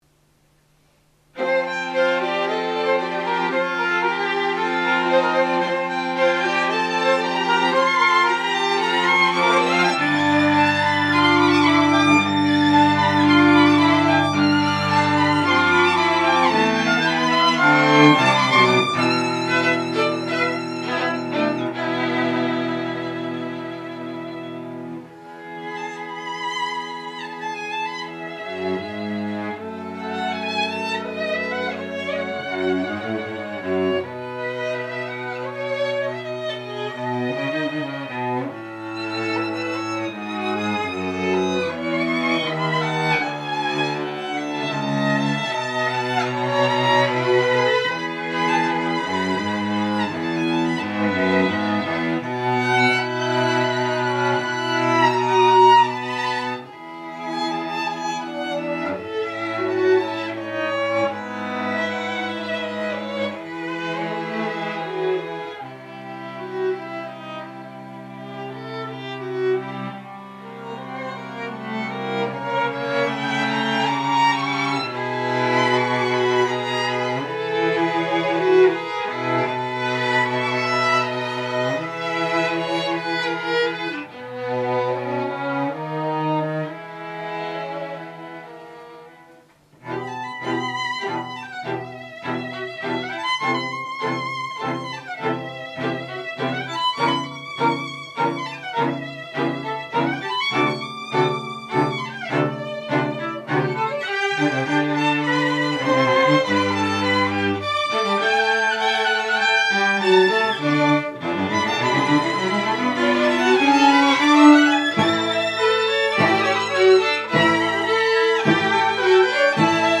演奏は、ノン・ジャンル弦楽四重奏団≪モーメント・ストリングカルテット≫です。